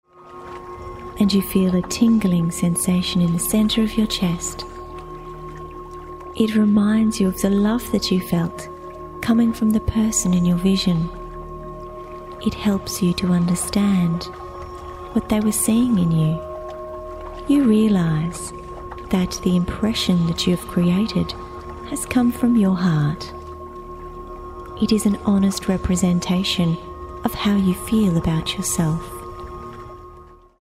Thin Thinking Self-Hypnosis